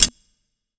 TC2 Perc13.wav